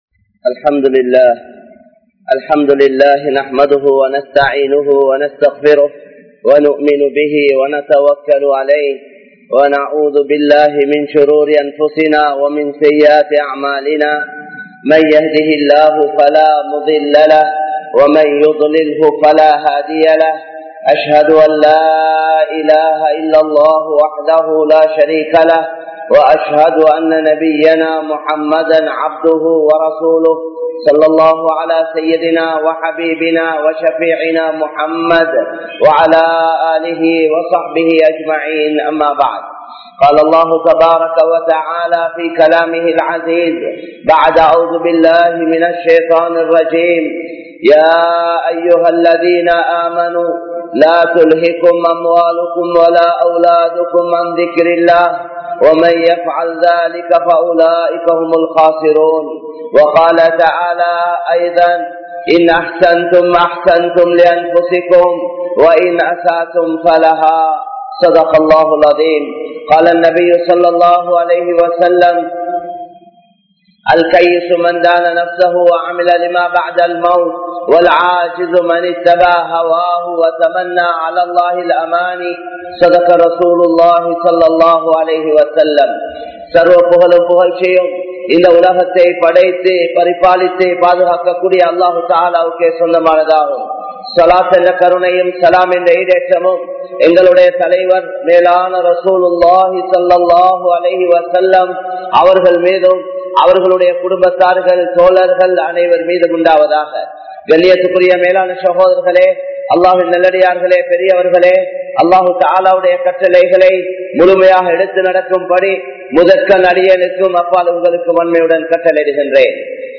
Dhunyaavin Moaham (துன்யாவின் மோகம்) | Audio Bayans | All Ceylon Muslim Youth Community | Addalaichenai
Negombo, Grand Jumua Masjith